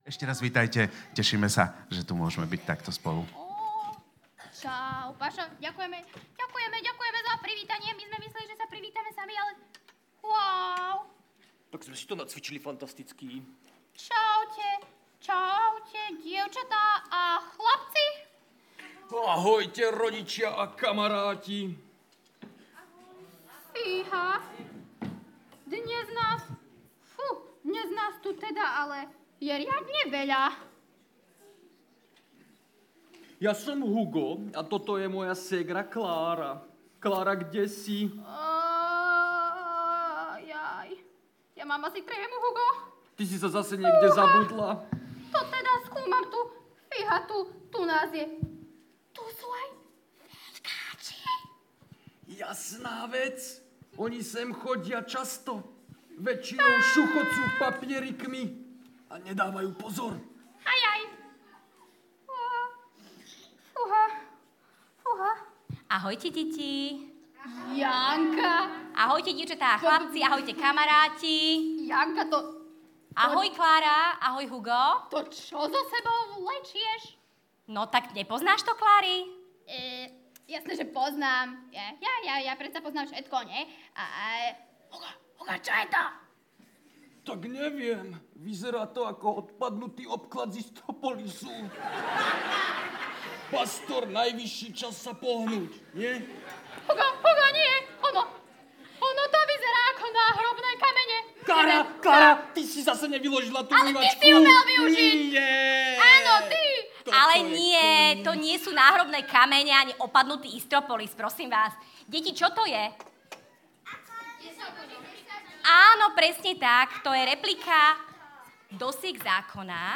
Prinášame vám kázeň v rámci špeciálnych detských bohoslužieb v BCC.